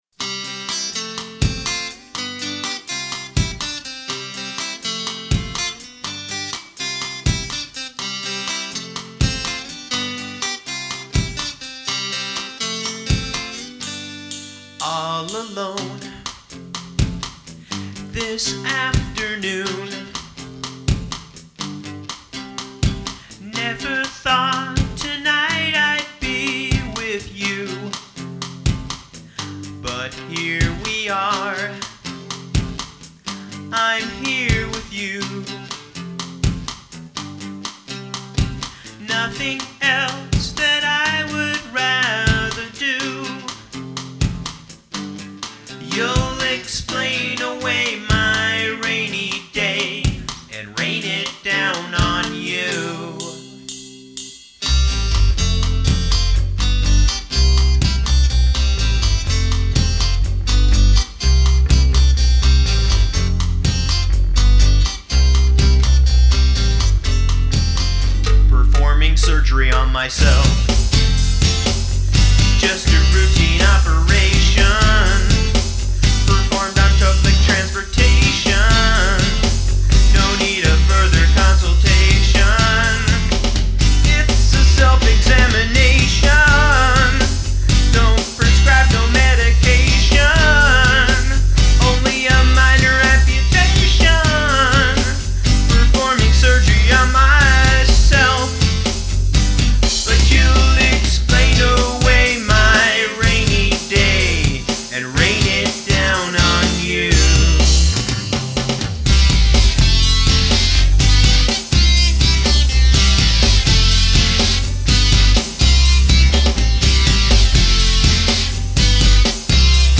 home recording